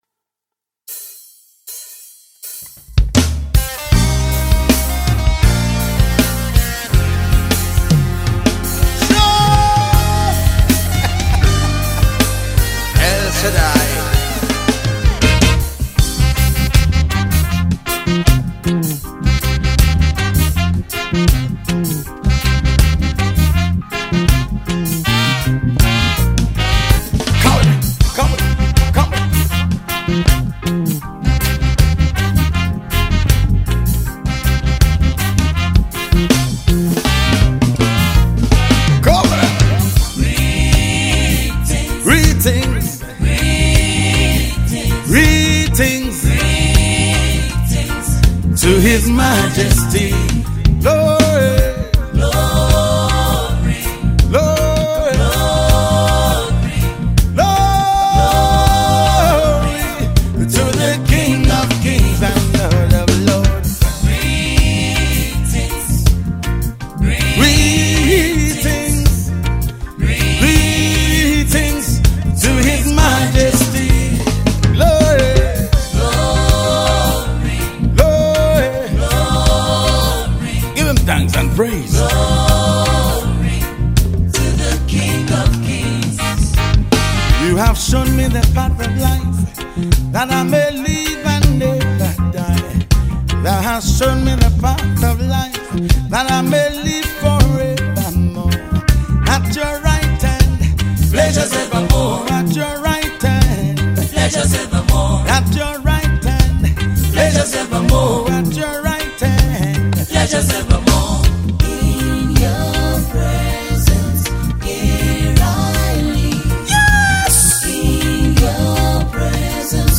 Gospel
poet spoken words and reggae gospel artist.